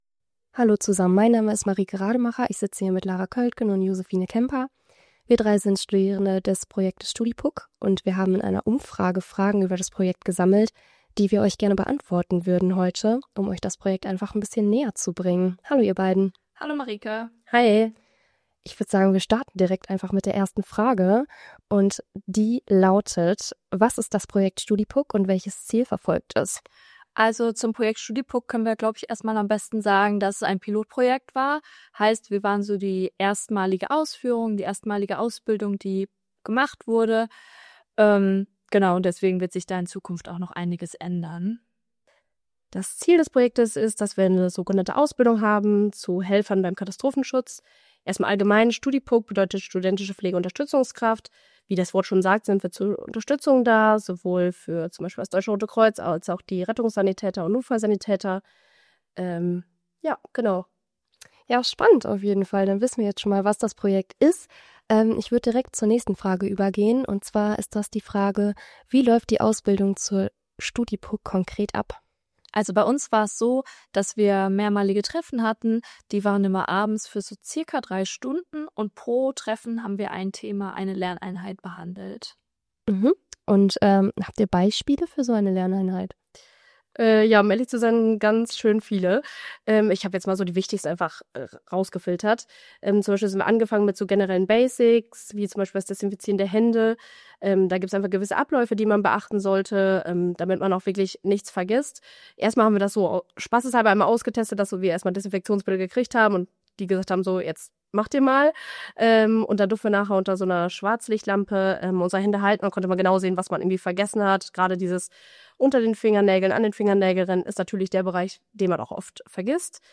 Das erzählen die drei Studierenden in der Aufnahme.